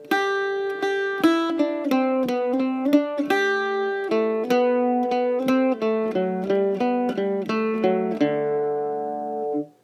Doyle’s Castle (2 part jig in G major or Ionian mode)
For those of you on whistle and/or flute, here are two alternatives for the A part where it goes below D. The first one is fairly simple, just play it an octave higher in the first phrase and then switch for the second phrase.
Doyle’s Castle (1st Alternative for A phrase)